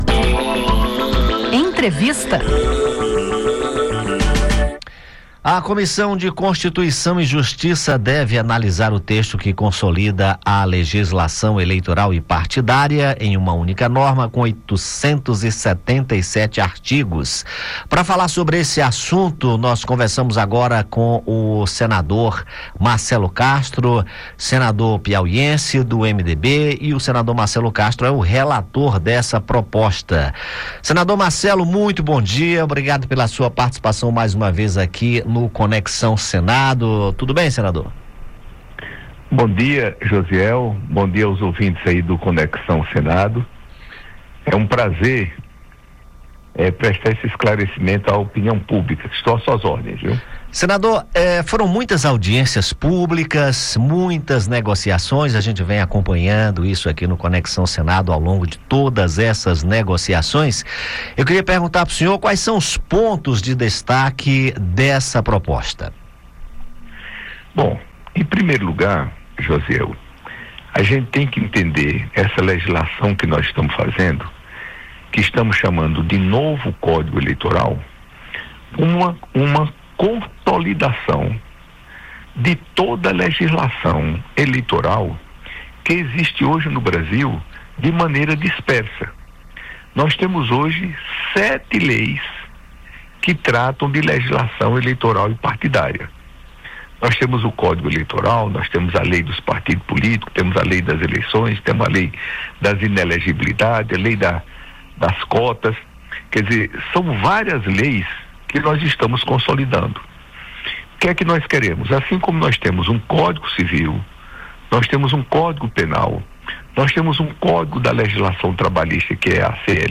O senador Marcelo Castro (MDB-PI), relator do projeto, destaca as principais mudanças e comenta os pontos onde ainda falta consenso.